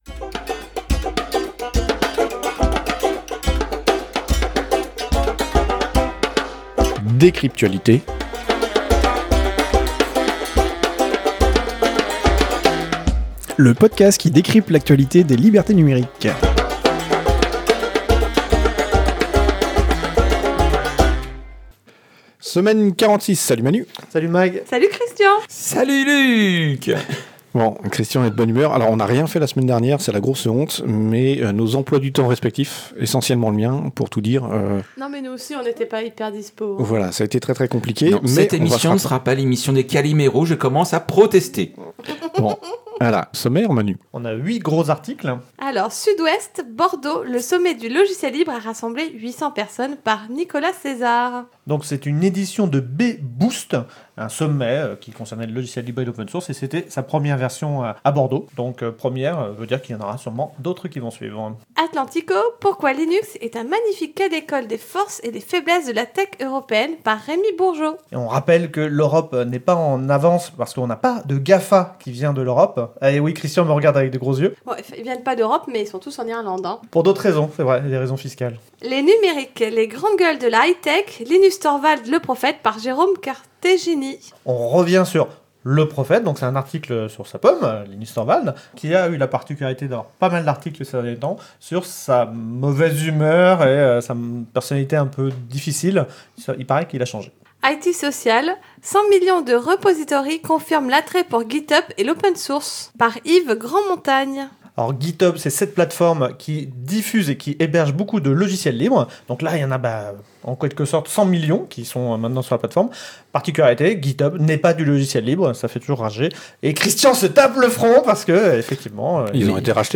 Lieu : April - Studio d'enregistrement